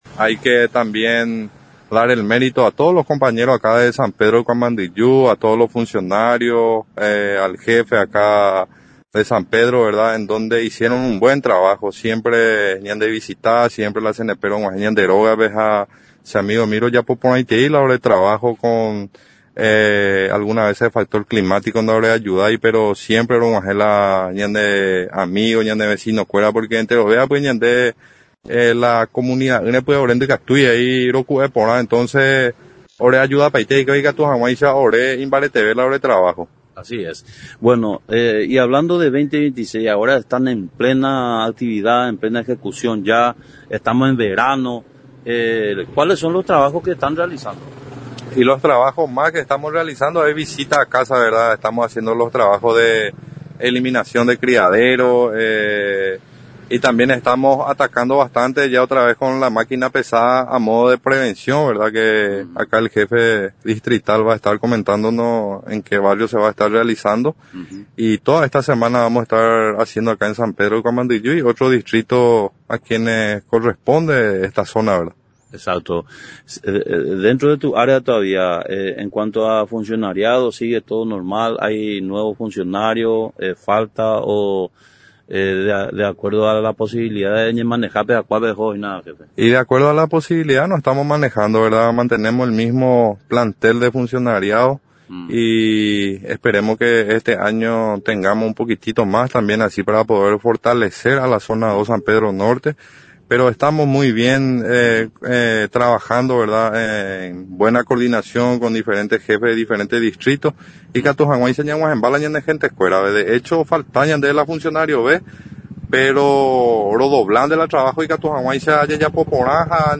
en conversación con nuestro medio explicó las labores de bloqueo que se vienen realizando en distintas comunidades, teniendo en encuentra los reportes de casos sospechosos de dengue registrados en la zona.